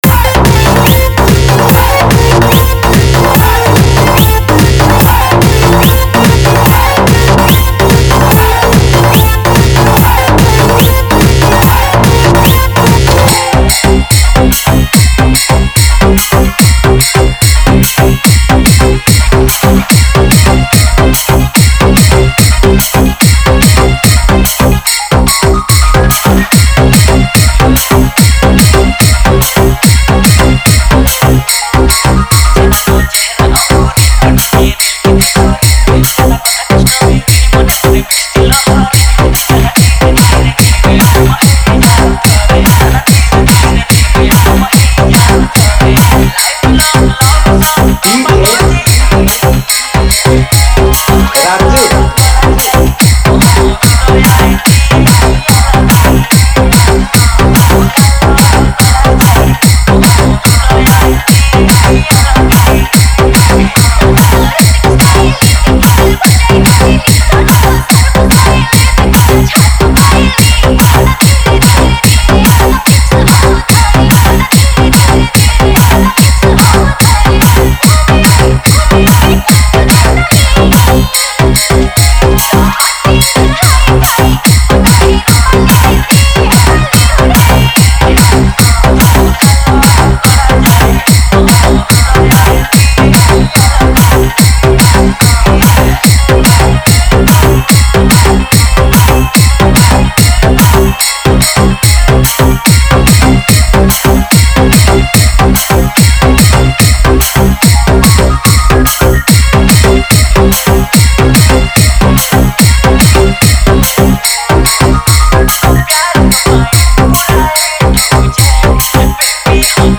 Category:  New Odia Dj Song 2021